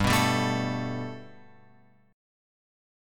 Gadd9 chord {3 2 5 2 x 5} chord